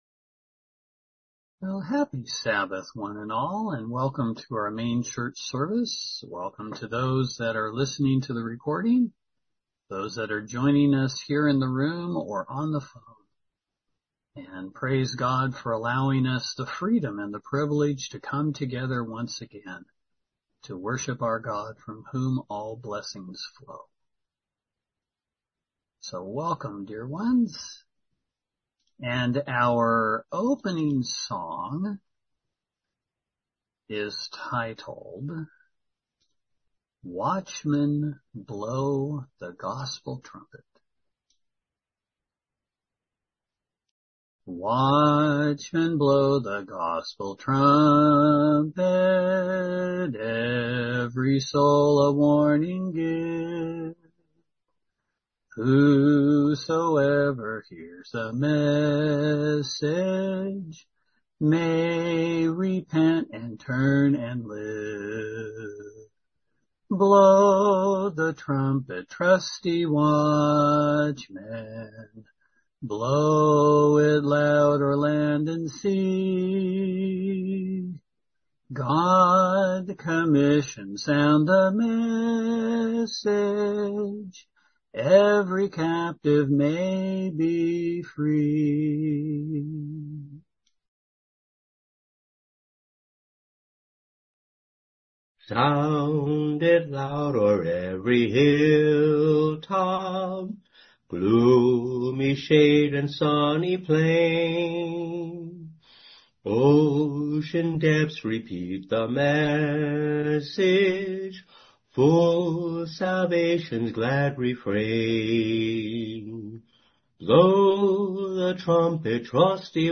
LTBL-sermon-(6-7-25).mp3